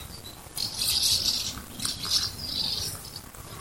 Später dann die Mehlschwalben, genauso ausgelassen am Himmel.
(Ich hoffe, das Anhängen von vier Sekunden Mehlschwalbenausgelassenheit funktioniert)